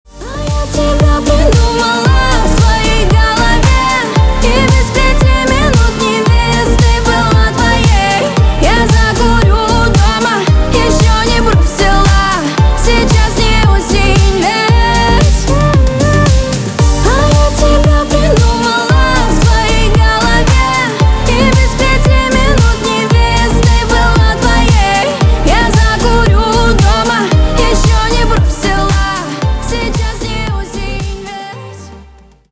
на русском на бывшего грустные